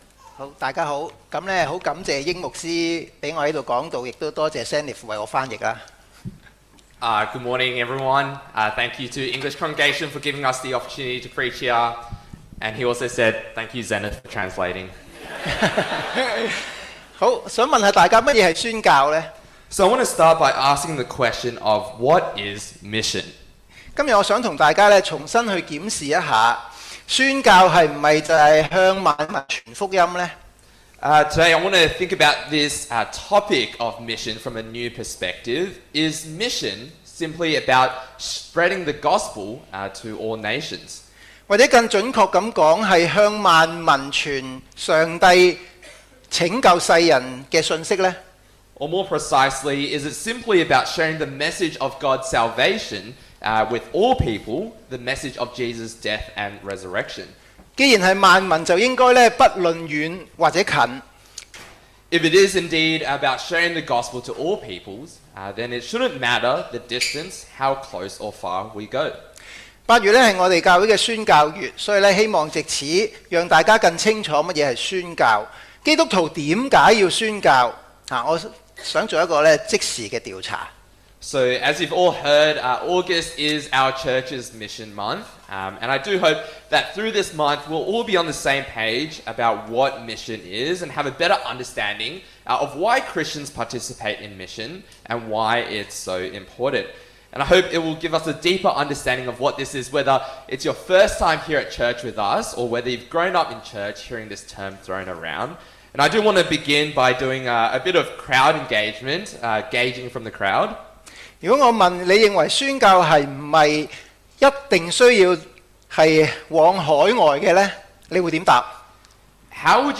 Sermons English - The Chinese Christian Church